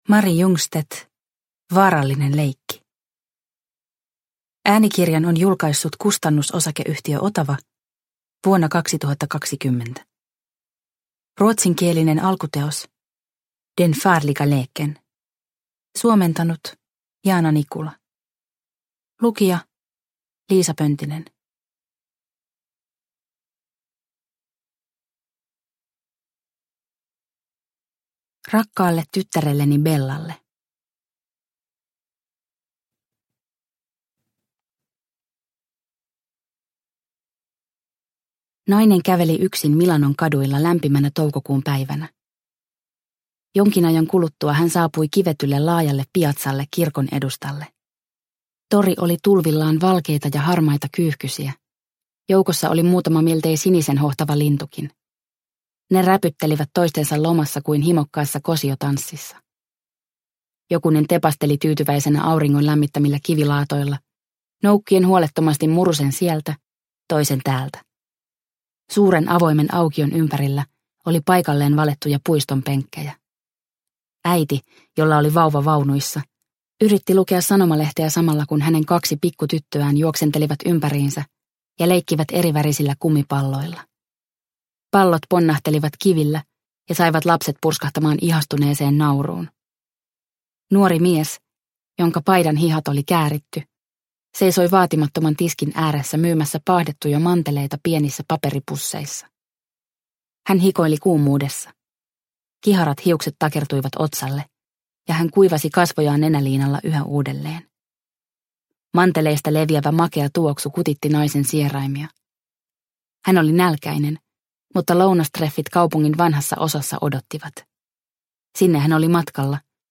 Vaarallinen leikki – Ljudbok – Laddas ner